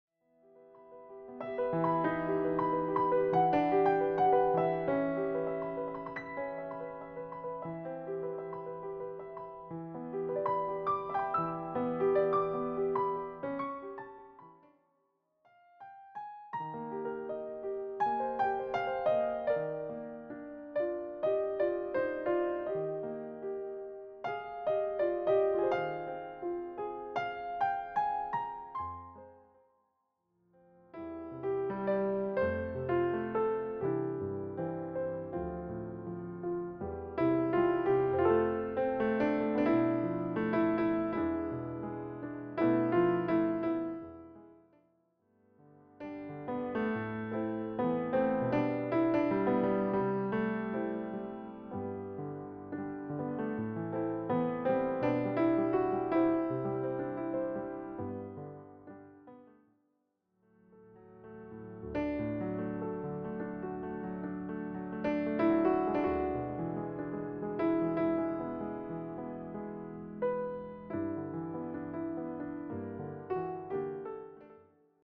a consistent, relaxed romantic mood